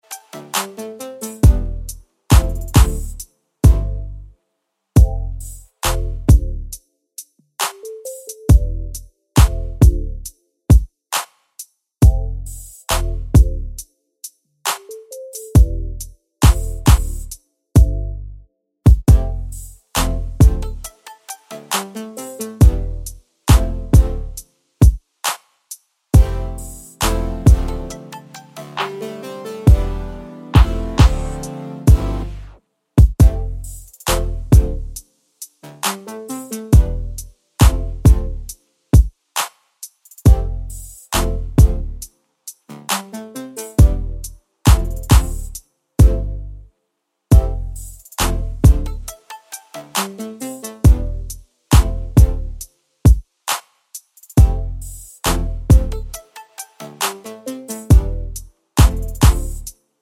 no Backing Vocals R'n'B / Hip Hop 3:10 Buy £1.50